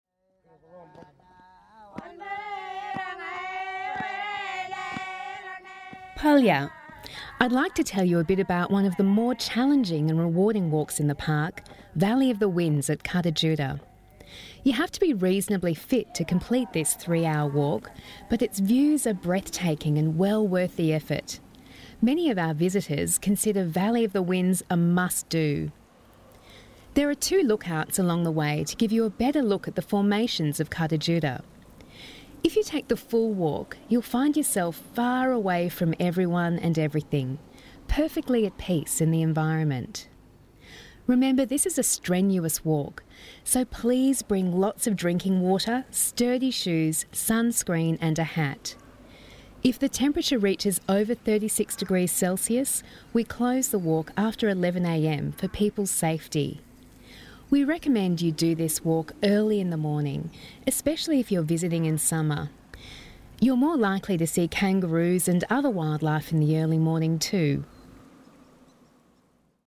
Valley of the Winds audio tour